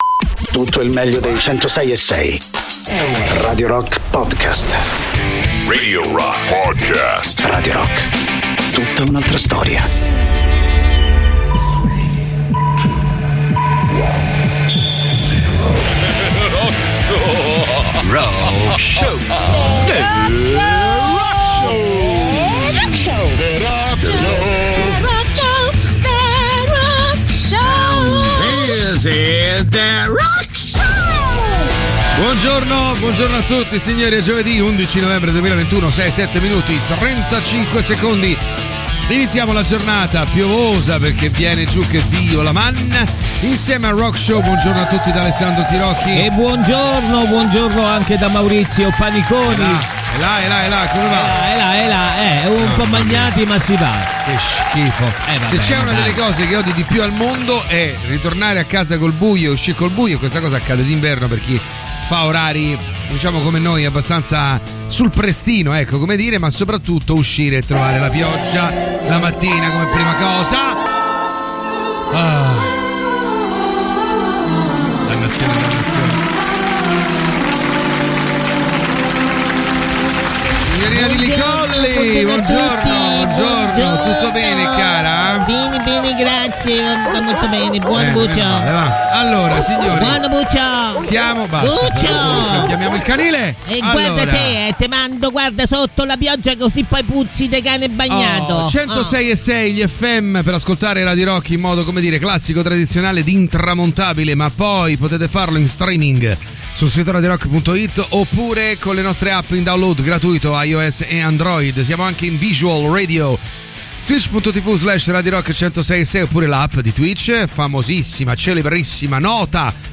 in diretta dal lunedì al venerdì